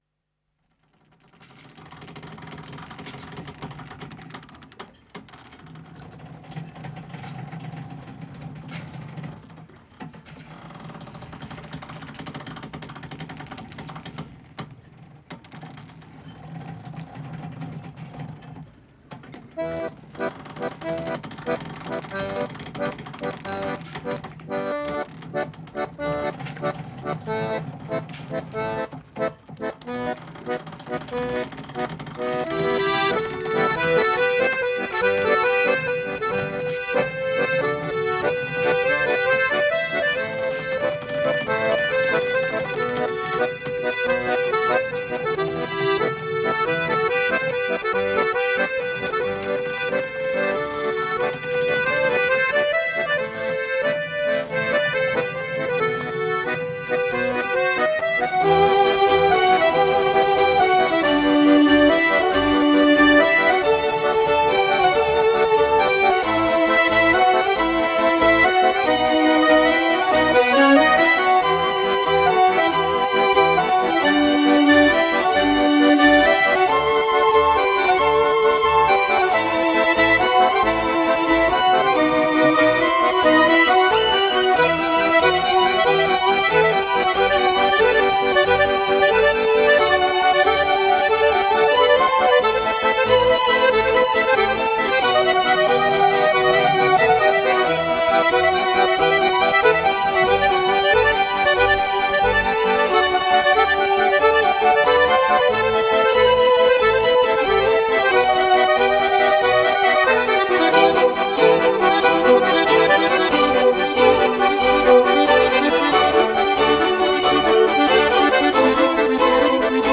Genre: World Music, Alternative, Neo-Classical, Neo-Folk